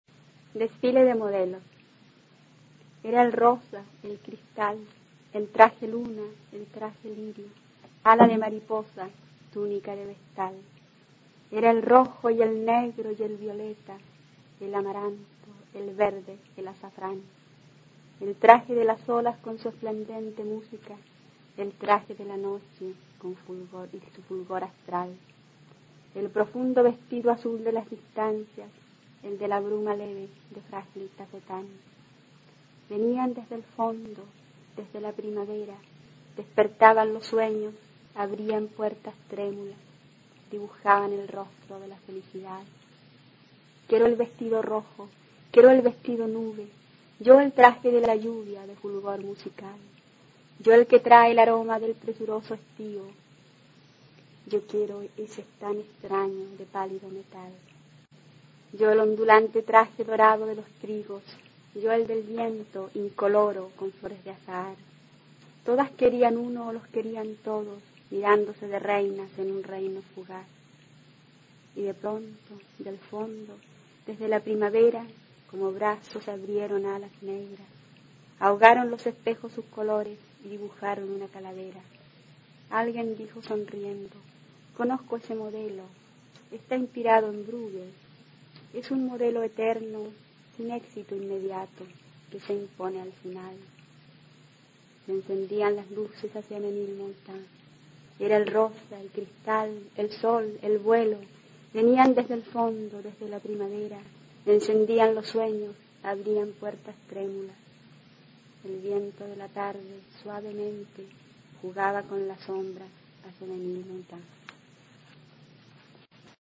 recitando su poema inédito Desfile de modelos.
Poema